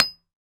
hammeroncement.ogg